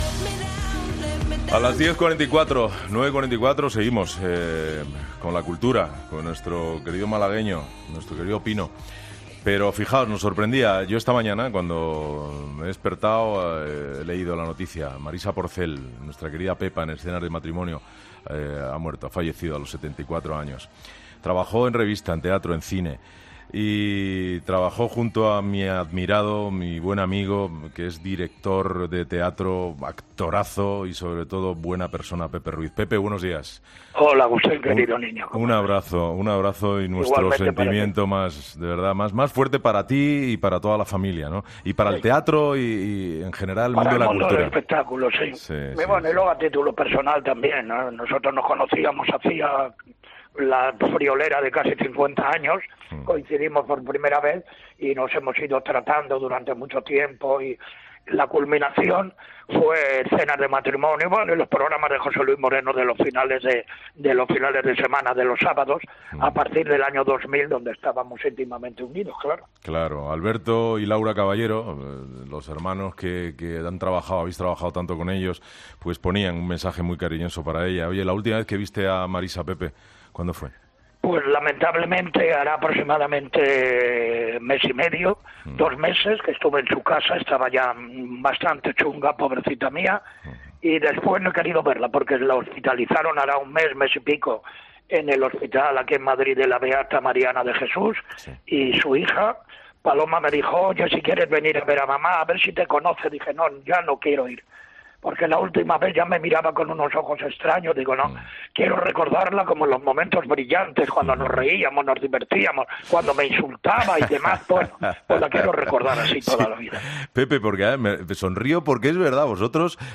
El actor Pepe Ruiz habla sobre Marisa Porcel fallecida en Madrid